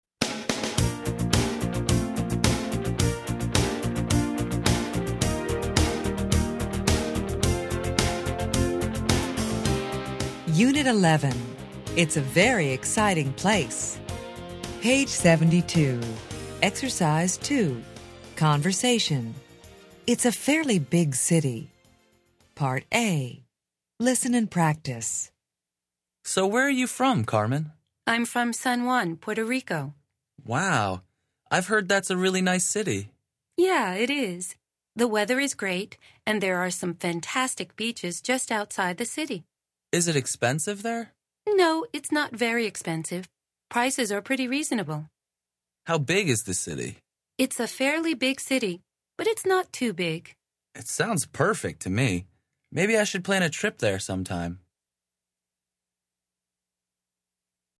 Interchange Third Edition Level 1 Unit 11 Ex 2 Conversation Track 32 Students Book Student Arcade Self Study Audio